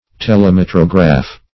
Search Result for " telemetrograph" : The Collaborative International Dictionary of English v.0.48: Telemetrograph \Tel`e*met"ro*graph\, n. [Gr. th^le far + me`tron measure + -graph.] A combination of the camera lucida and telescope for drawing and measuring distant objects.